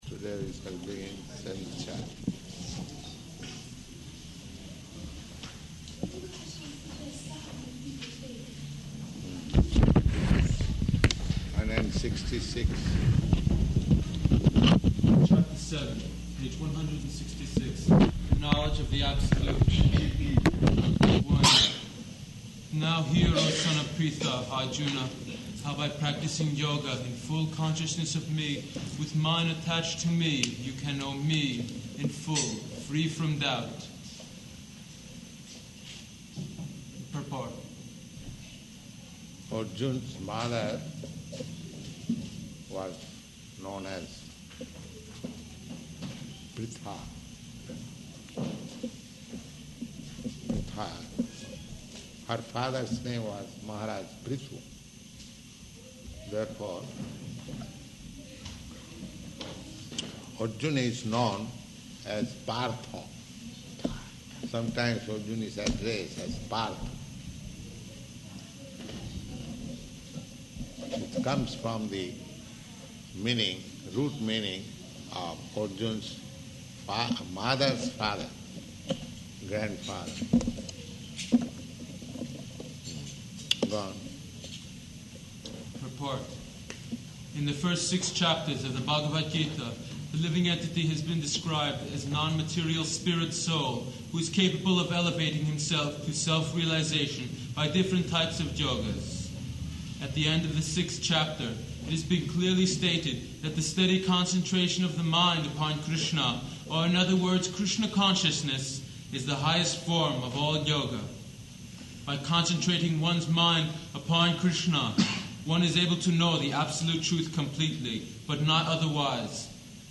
Bhagavad-gītā 7.1 --:-- --:-- Type: Bhagavad-gita Dated: February 22nd 1969 Location: Los Angeles Audio file: 690222BG-LOS_ANGELES.mp3 Prabhupāda: Today we shall begin Seventh Chapter.